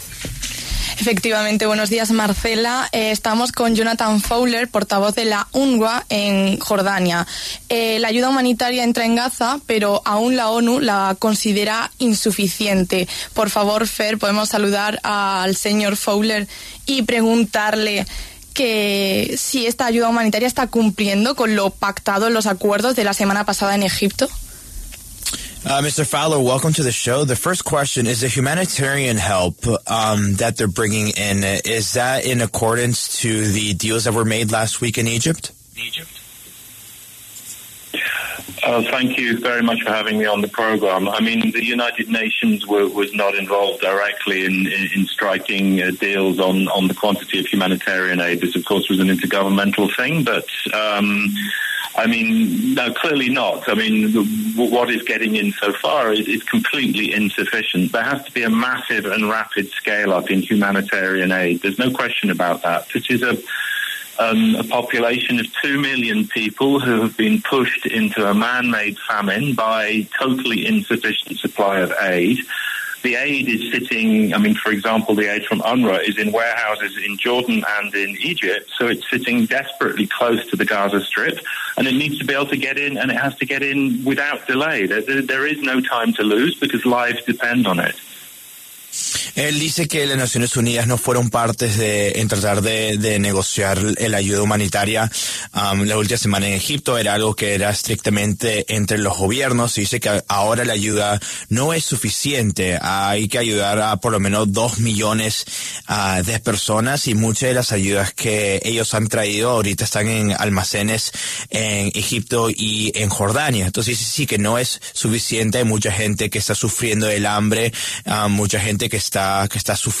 estuvo en los micrófonos de La W Radio y explicó la situación humanitaria en Gaza.